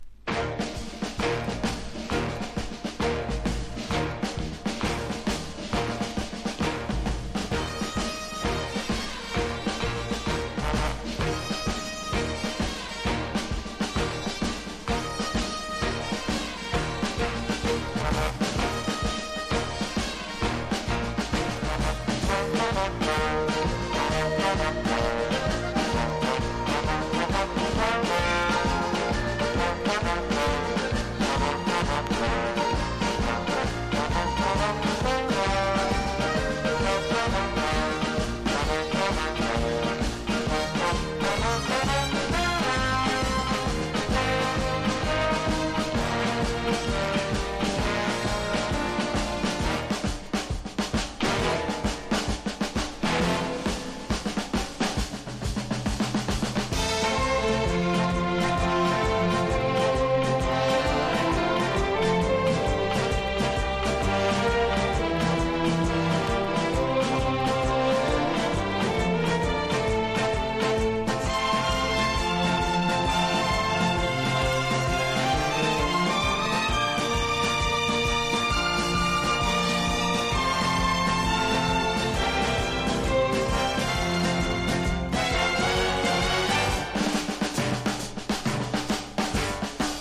# EASY LISTENING
• 盤面 : EX+ (美品) キズやダメージが無く音質も良好 (前面コーティングジャケット)